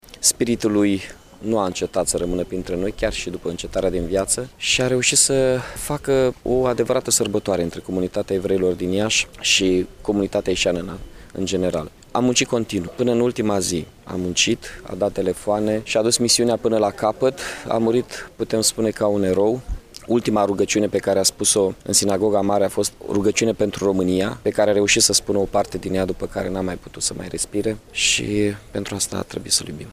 Primarul Iaşului, Mihai Chirica, şi-a exprimat regretul pentru pierderea suferită de comunitatea evereiască.